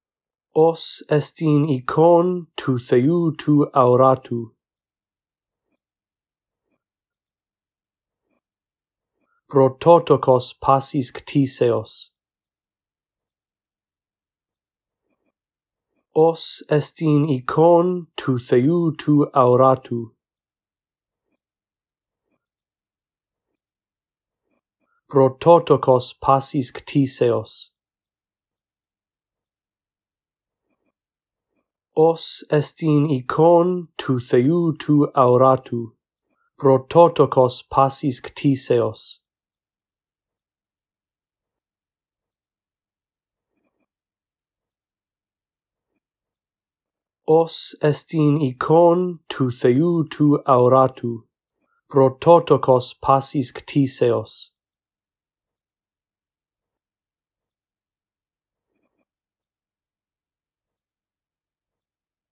In this audio track, I read through verse 15 a phrase at a time, giving you time to repeat after me. After two run-throughs, the phrases that you are to repeat become longer.